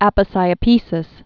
(ăpə-sīə-pēsĭs)